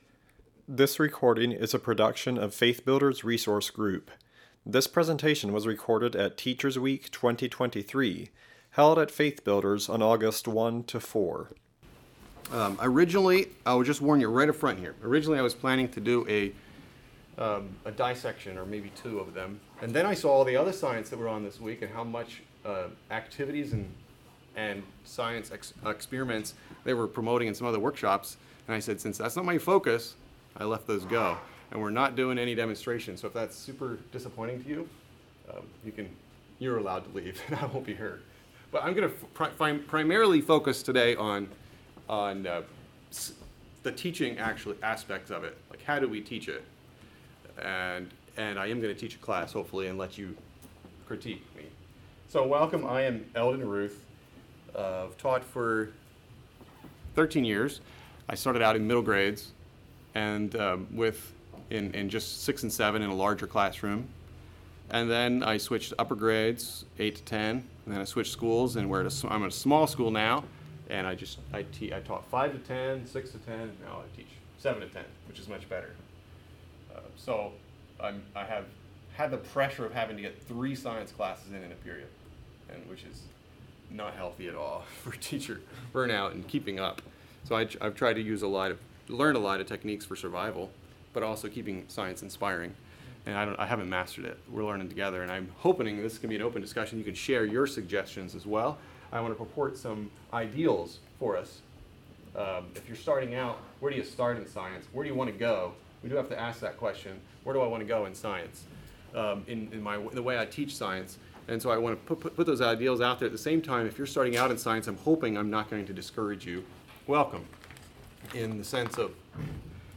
Home » Lectures » Bolstering Retention in Science
How can I ensure that students are engaged and retain the core material? Aimed at middle and upper grades, this class is taught by a teacher from a multi-grade setting.